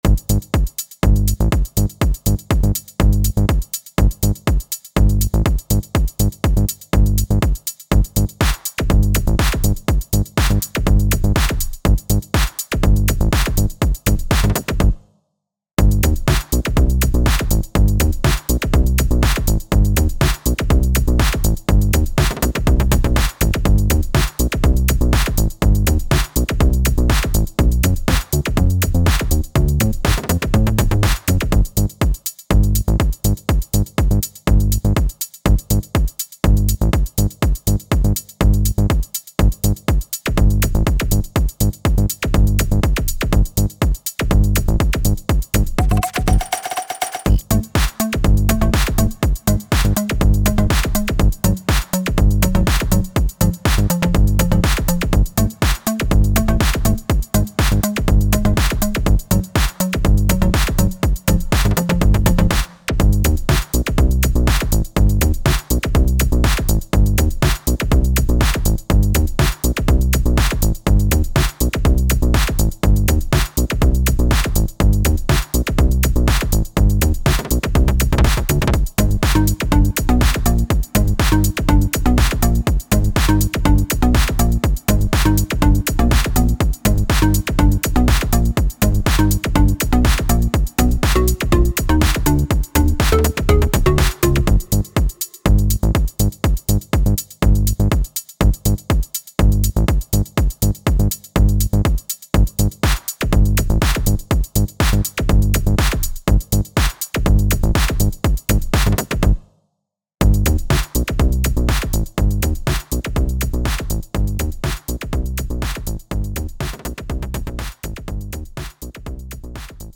タグ: Beat EDM 変わり種 暗い 電子音楽 コメント: 暗くてミニマルな電子音楽。